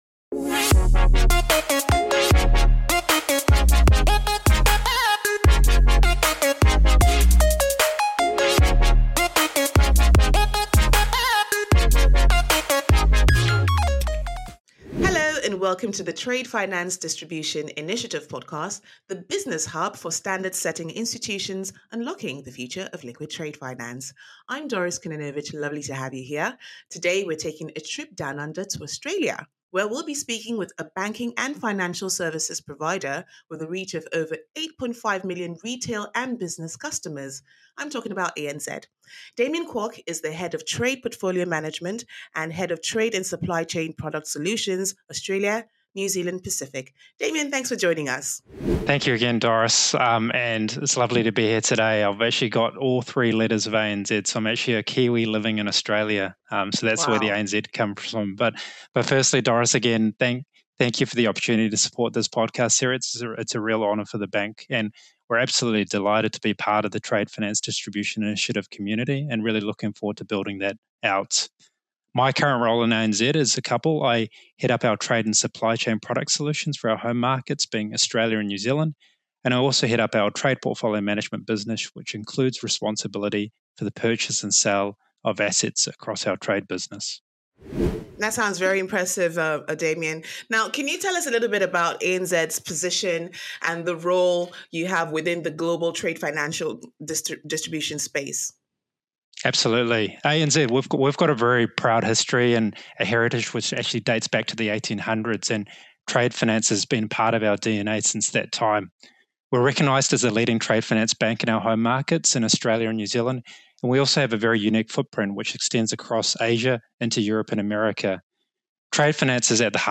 Episode 26 is a solidly-packed chat